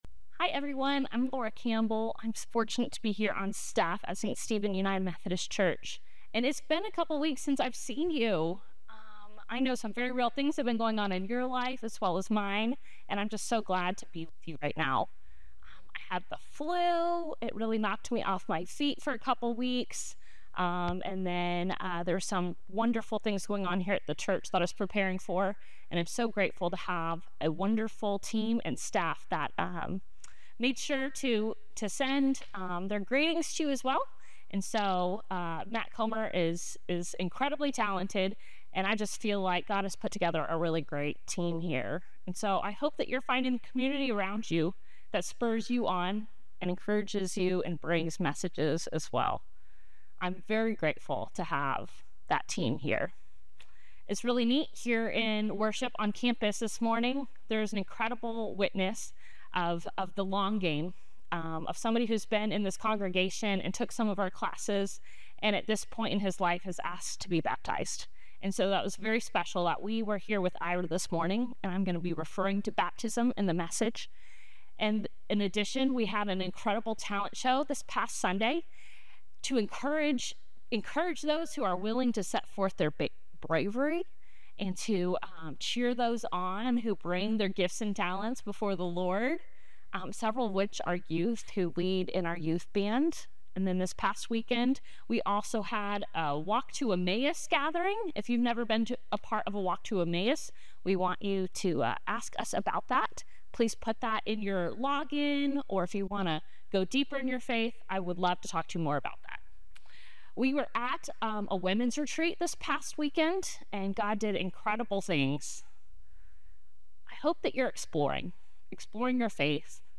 This week, we turn to one of Jesus' inner-circle: Peter. Sermon Reflections: Our earthly fathers and our Heavenly Father can give us different kinds of knowledge.